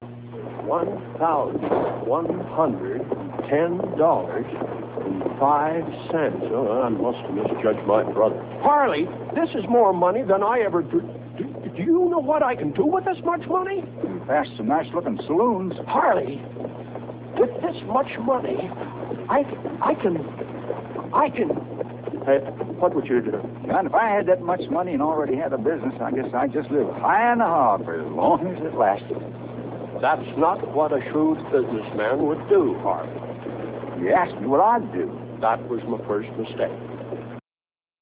Money1.real audio-41kbJohn asking harley what he would do with 1100 dollars!